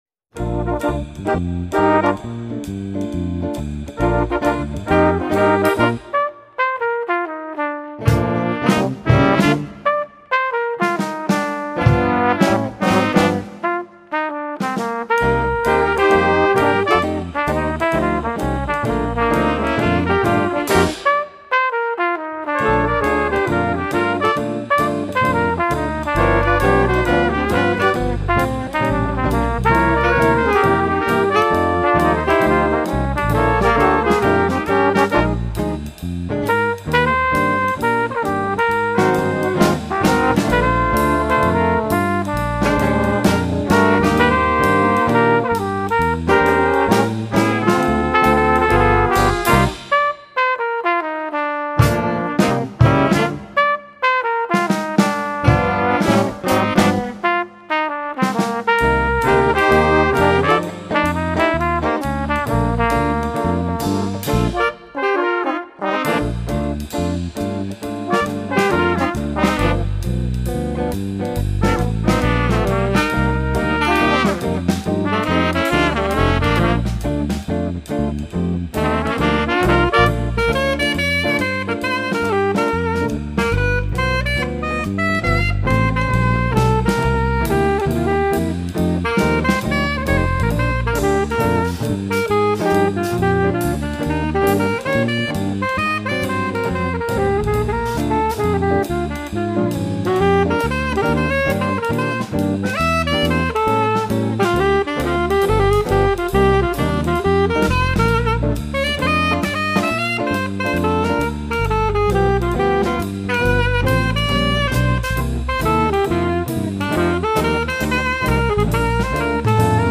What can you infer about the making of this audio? Below are the tracks that we recorded while in the studio.